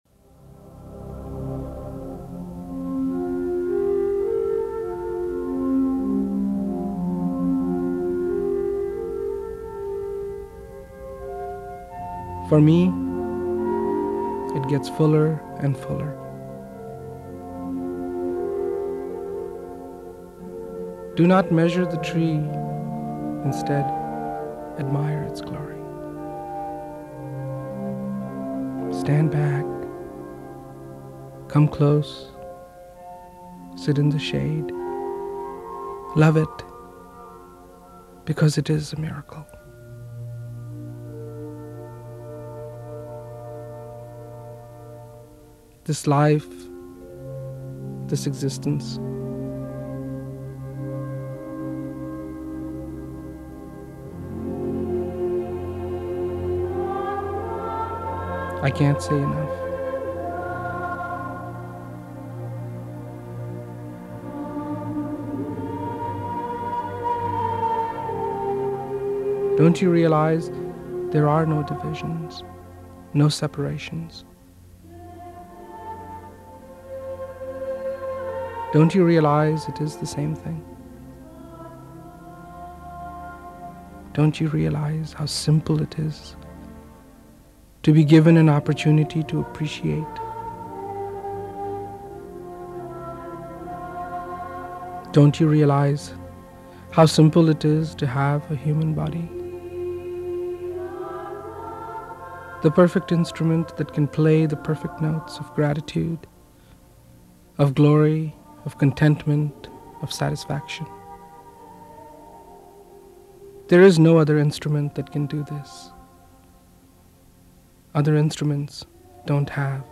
It was a combination of songs recorded by four followers and two sections of Rawat's speeches with background music that attempts to enhance Rawat's impact.
Mp3 copies of these excerpts are recorded at high quality (256Kbps) to ensure no whispered nuance or frenzied climax is missed.
Prem Rawat Inspirational Speaker in Lisbon, Portugal, 2001